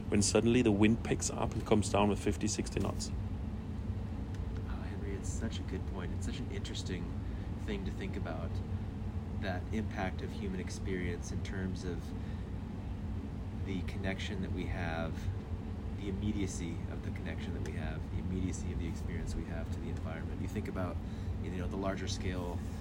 Es handelt sich um ein KI-basiertes Enhance-Werkzeug, mit dem Sprache vollautomatisch von Artefakten befreit wird. Dazu gehören alle (!) Arten von Hintergrundgeräuschen, inklusive Hall durch zu großen Mikrofonabstand oder ungleichmäßig rumpelnde Schiffsmotoren.